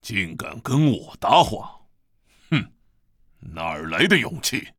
文件 文件历史 文件用途 全域文件用途 Hartz_tk_01.ogg （Ogg Vorbis声音文件，长度4.8秒，103 kbps，文件大小：60 KB） 源地址:地下城与勇士游戏语音 文件历史 点击某个日期/时间查看对应时刻的文件。